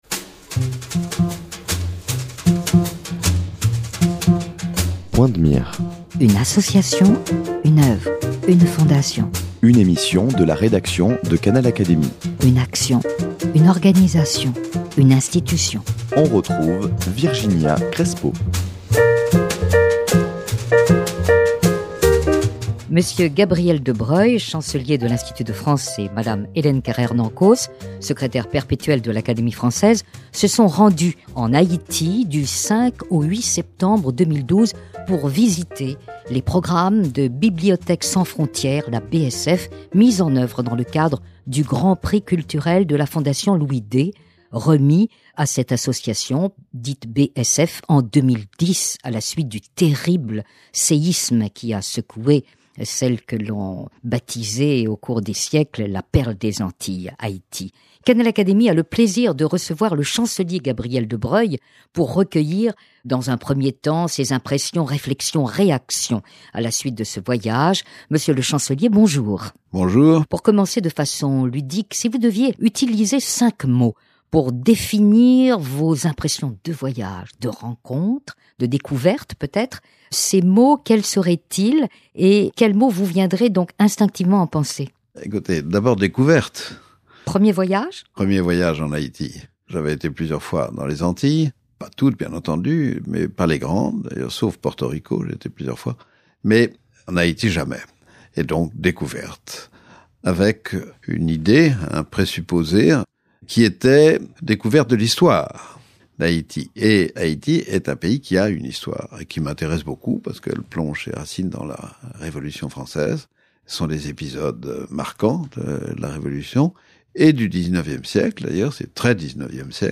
Dans cette première émission d’une série de trois, le chancelier de Broglie raconte l’état dans lequel il a trouvé Haïti et en rappelle les principaux moments historiques.